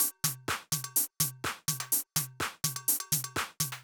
Drumloop 125bpm 07-B.wav